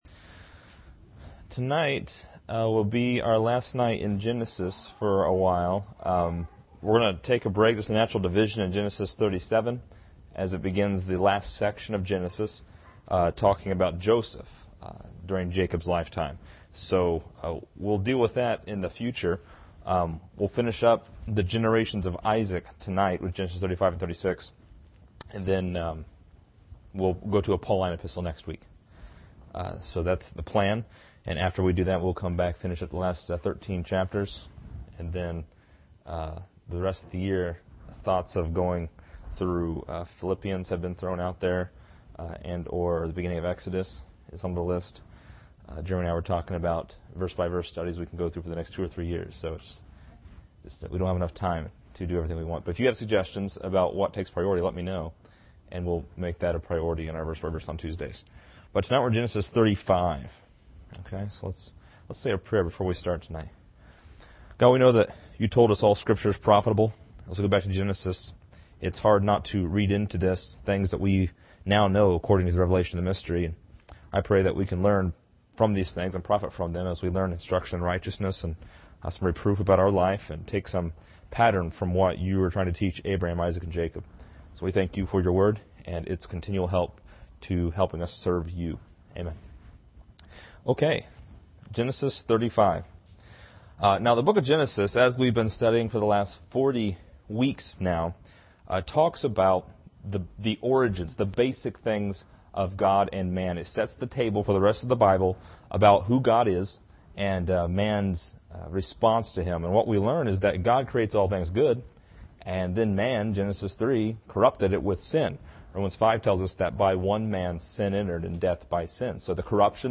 This lesson is part 40 in a verse by verse study through Genesis titled: Jacob Returns to Bethel.